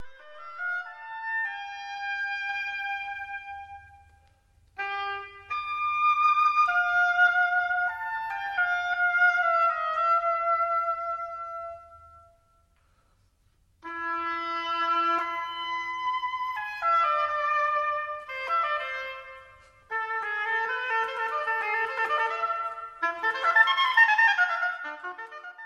Oboe
Lembra a ese son típico do encantador de serpes, verdade?
oboe.mp3